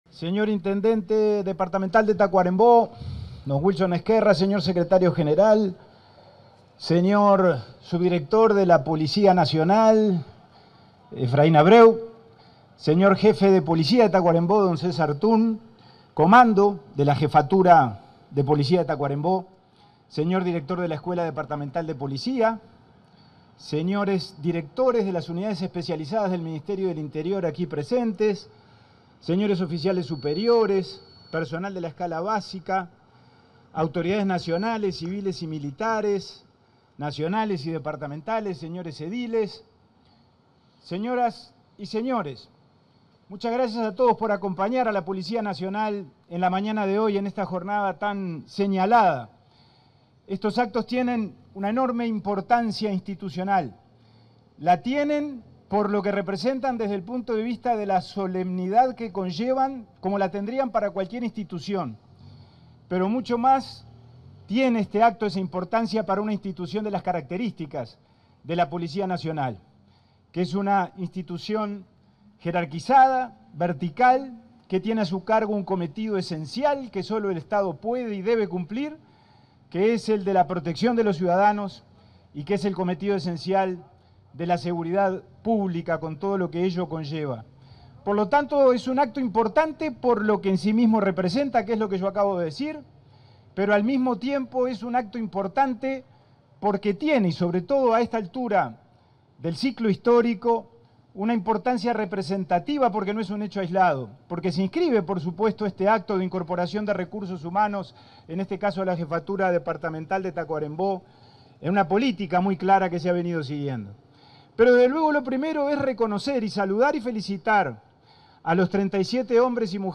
Palabras del ministro interino del Interior, Pablo Abdala
En el marco de la ceremonia de egreso de 37 nuevos agentes de la Promoción n.° 58 en la Jefatura de Tacuarembó, este 31 de enero, se expresó el